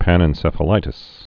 (pănĕn-sĕfə-lītĭs)